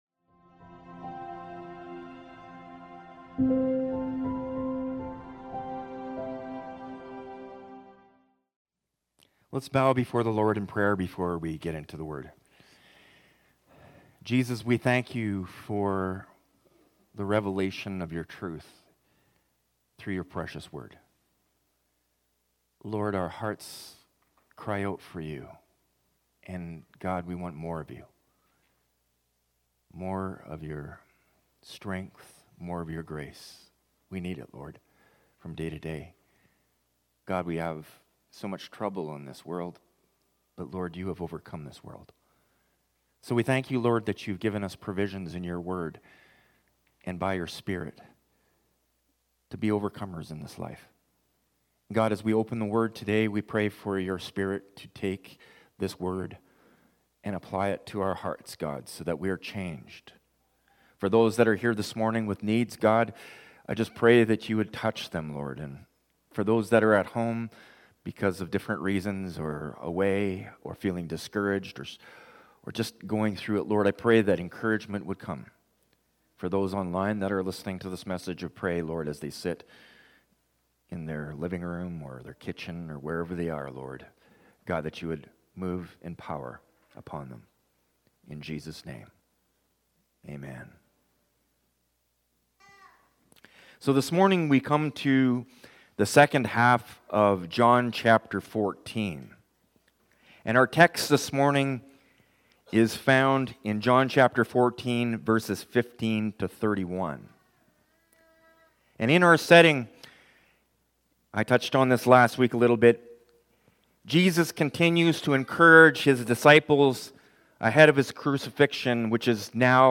Sermons | Hillside Community Church